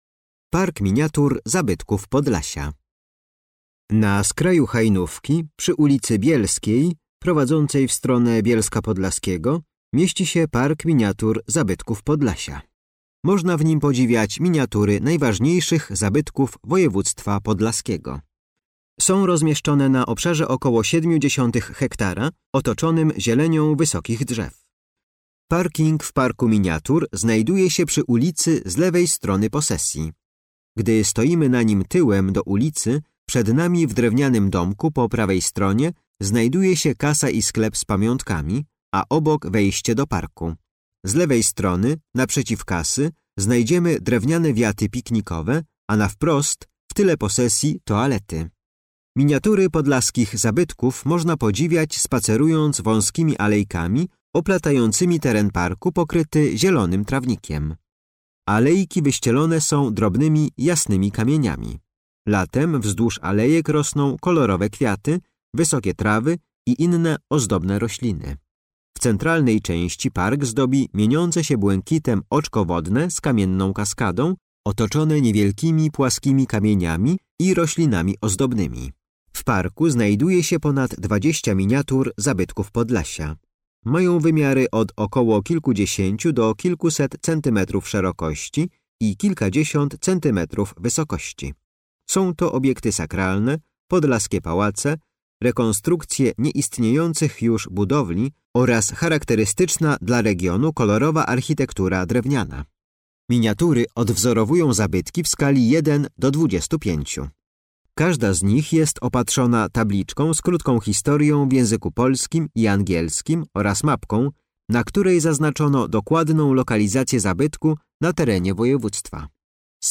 Audiodeskrypcja do Parku Miniatur Zabytków Podlasia